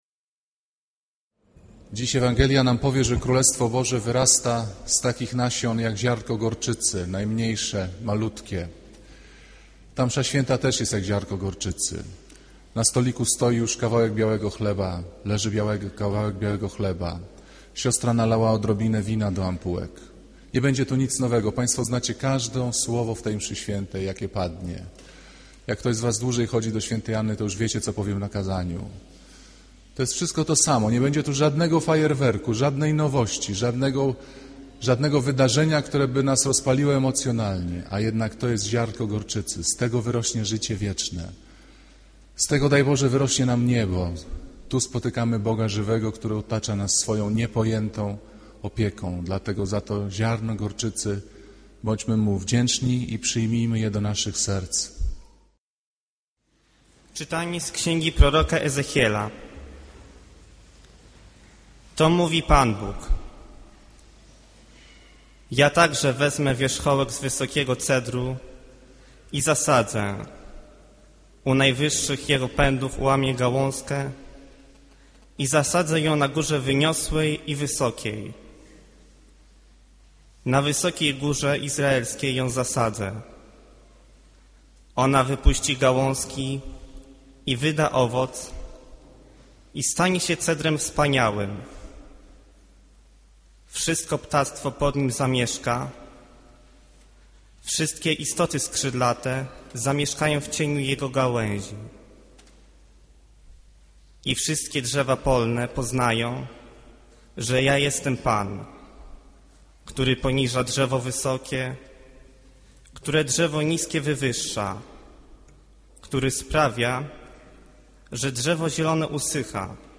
Kazanie z 14 czerwca 2009r.